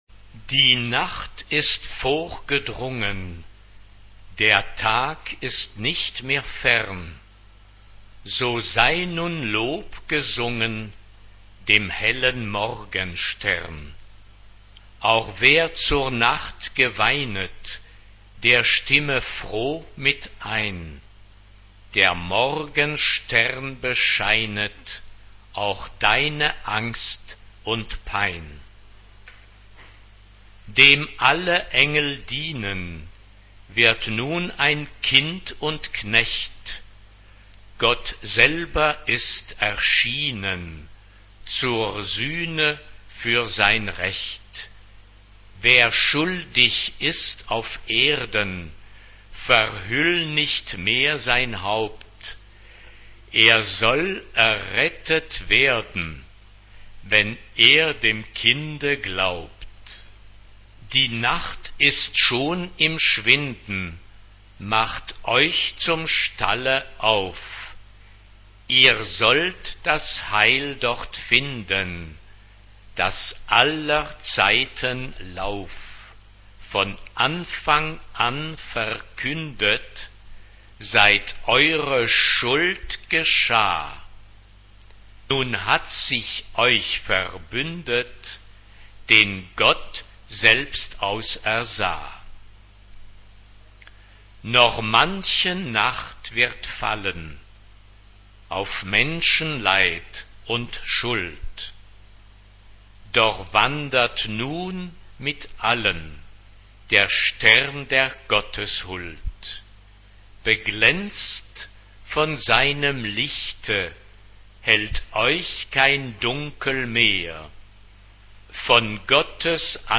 Tonart(en): c-moll